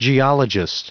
Prononciation du mot geologist en anglais (fichier audio)
Prononciation du mot : geologist